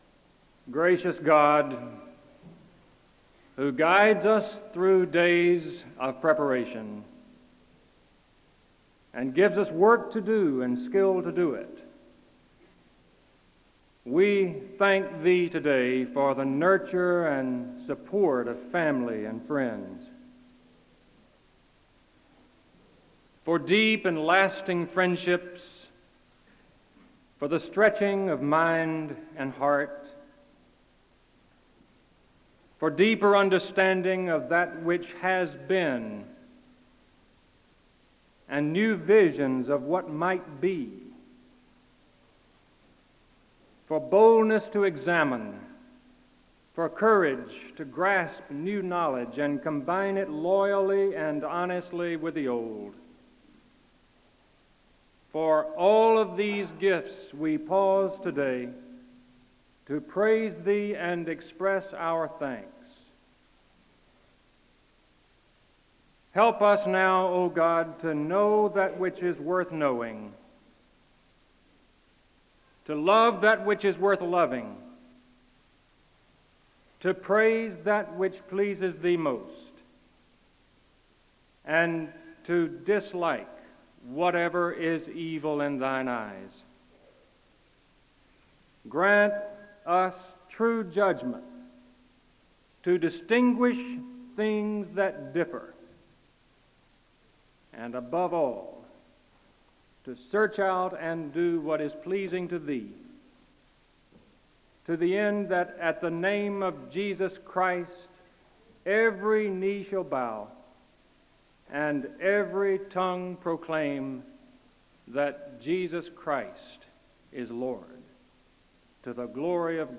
The service begins with a word of prayer (0:00:00-0:02:07). The choir sings a song of worship (0:02:08-0:04:57). There are Scripture readings from Isaiah 51:1-8 and selected verses from Hebrews 11 and 12 (0:04:58-0:10:11). A welcome is extended to the guests and directions are given for the commencement ceremony (0:10:12-0:12:56).
Everyone is encouraged to participate in reciting the litany of mission (1:03:38-1:06:41). The service is closed in prayer (1:06:42-1:08:54).